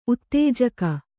♪ uttējaka